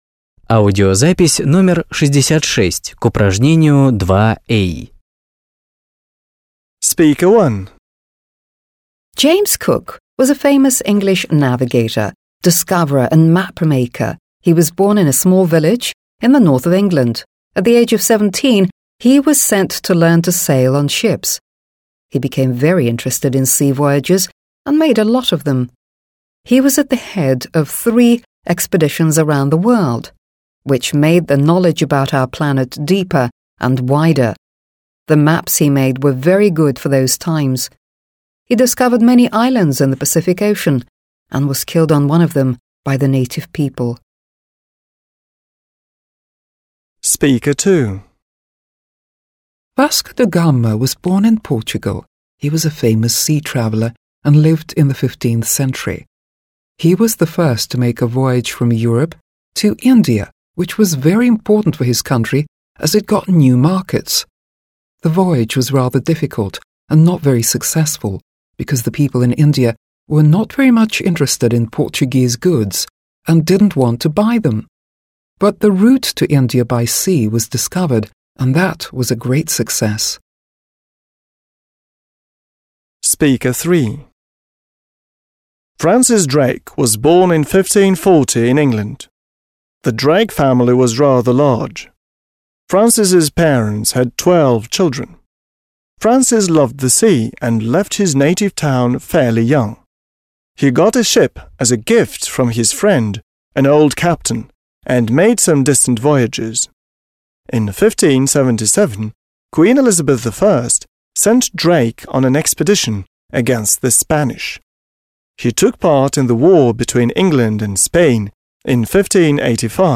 2. A. Listen to three speakers, Audio (66), and match the statements (a-d) with what they say.